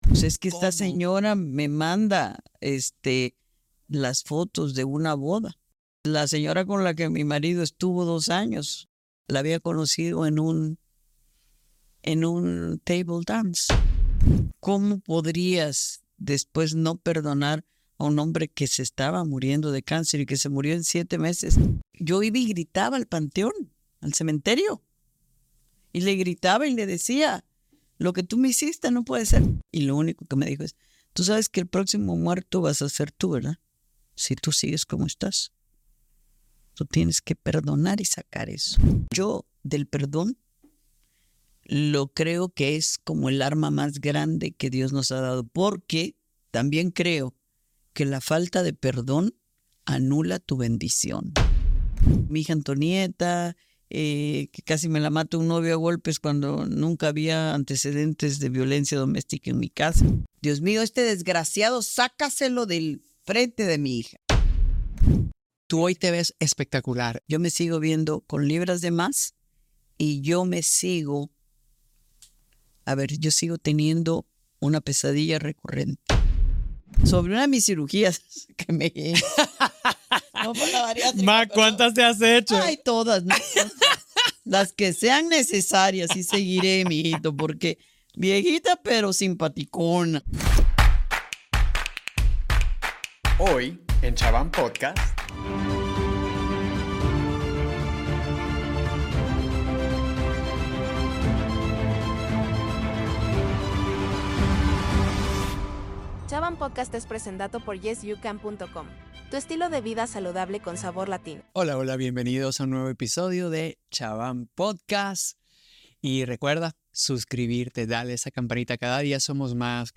Soy Alejandro Chabán y hoy tengo el honor de presentarles una entrevista muy especial con una mujer extraordinaria, una de las periodistas más reconocidas y respetadas en el mundo hispano: María Antonieta Collins.